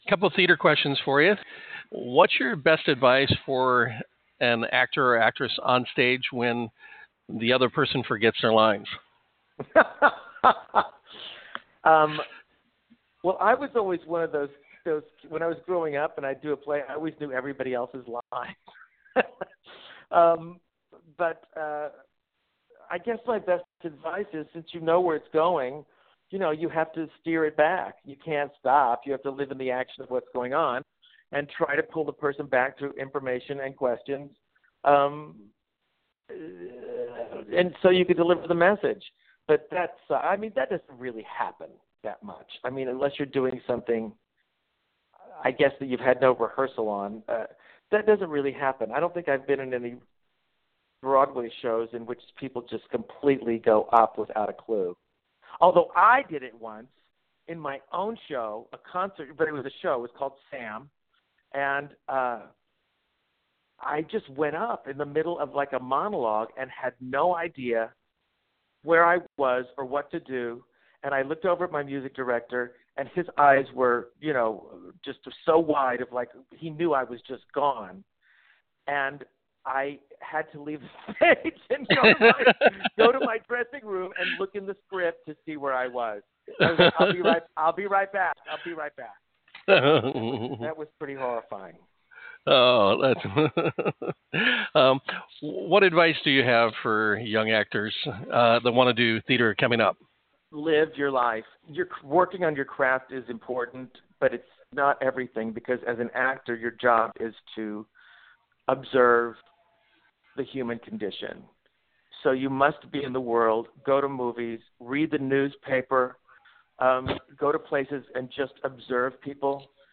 We asked Sam a few questions our Entertainment World fans wanted to know about acting.